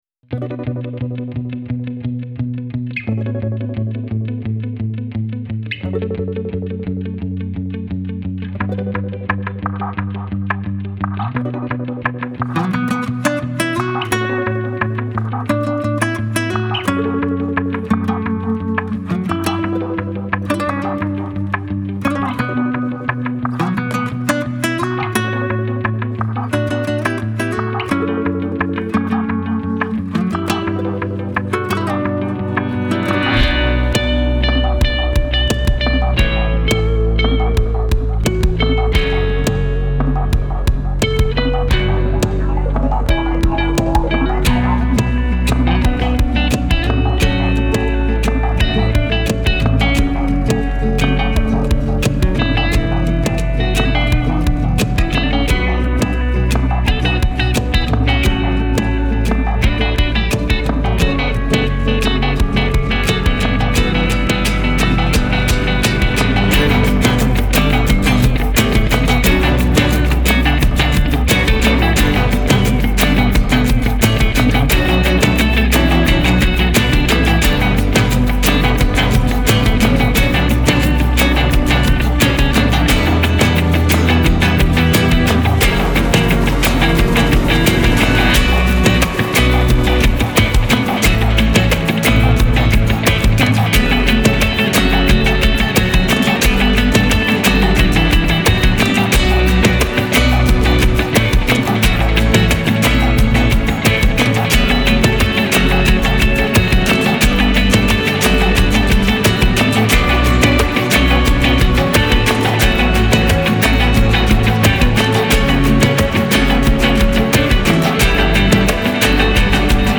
Genre : Latin